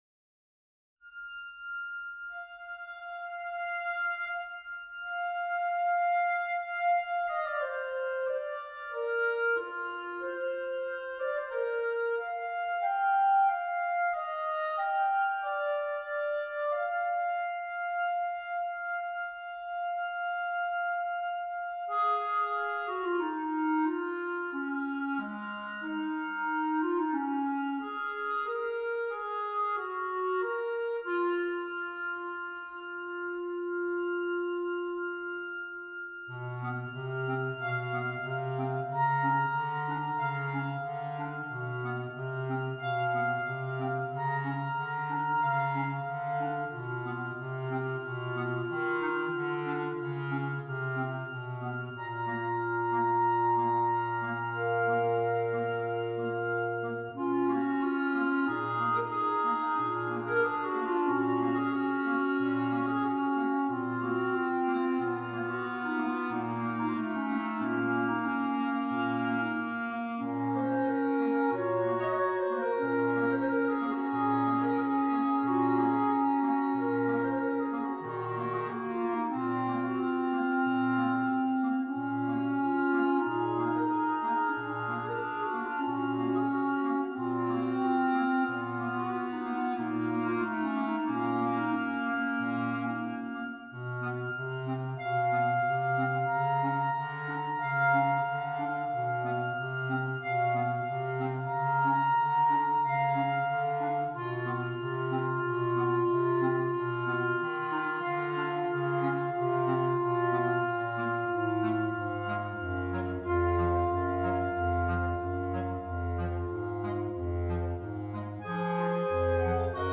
for clarinet quintet: